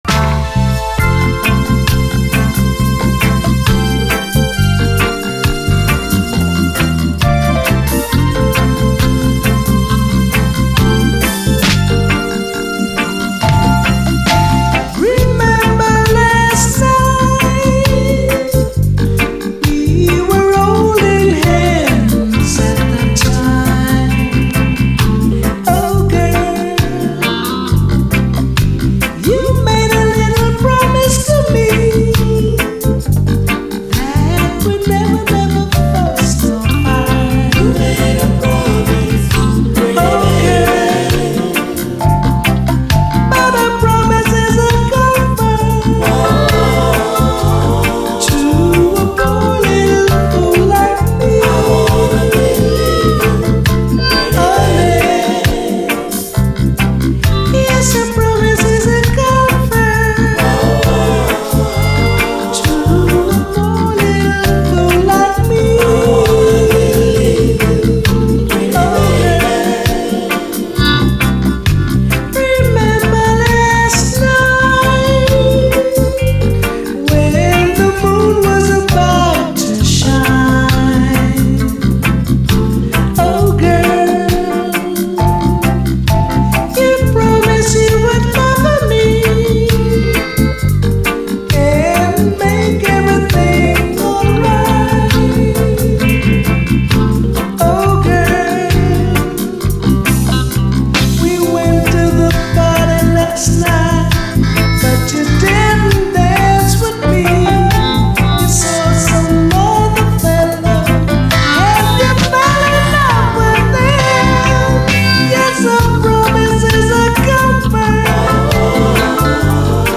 REGGAE
トロトロのファルセットにまどろむジャマイカン・ラヴァーズ！